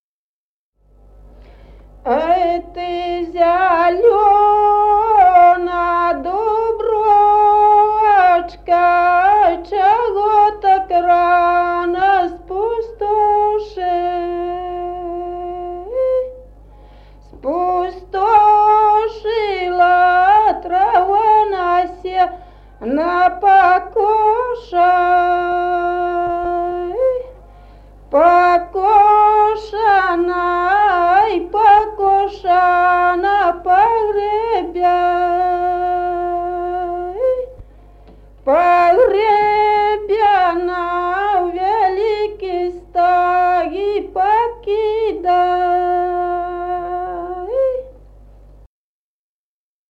Народные песни Стародубского района «Ай, ты зелёна дубровочка», покосная.